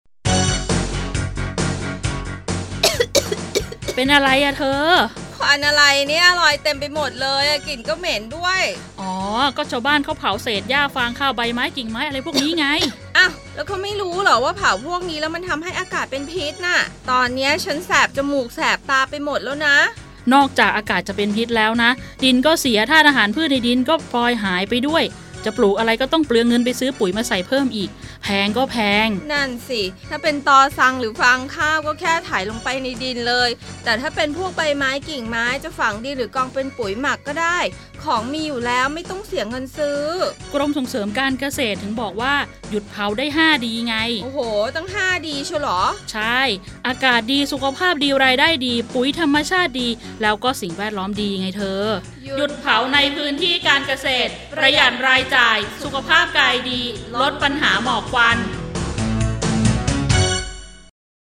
สปอตหยุดเผาพื้นที่การเกษตร ลดปัญหาหมอกควัน